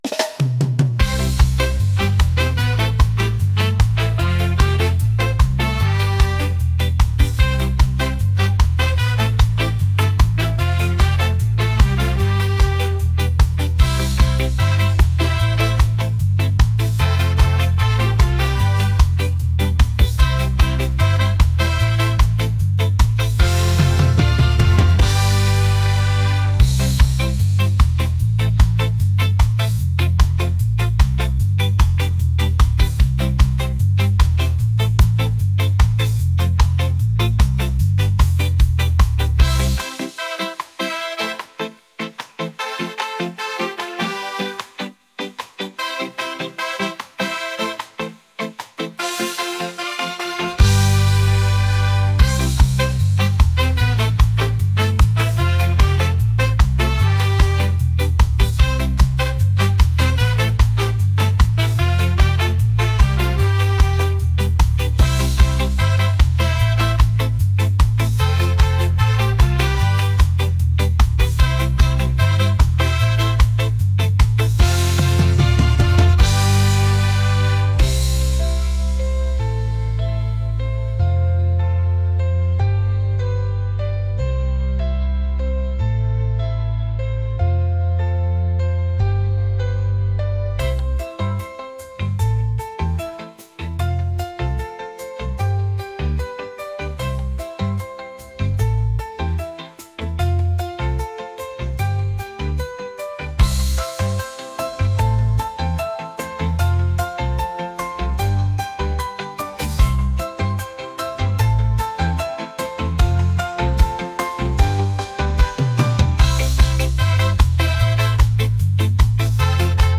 reggae | world